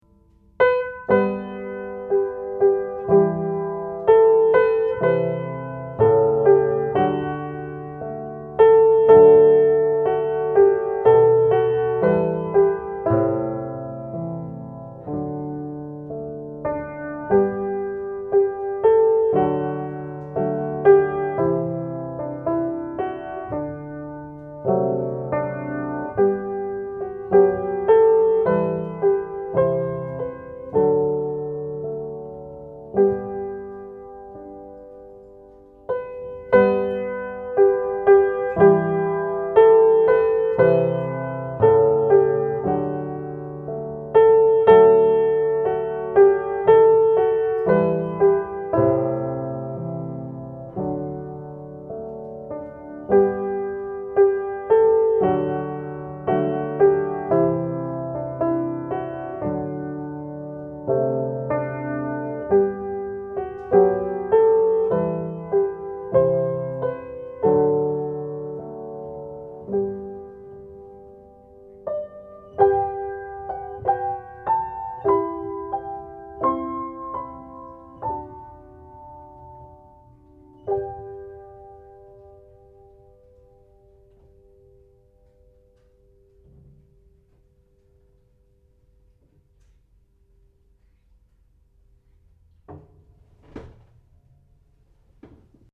Cominciamo una settimana importante per questo blogghetto con la dovuta solennità: ecco l'inno nazionale svedese suonato al pianoforte da Jens Lekman.
San Francisco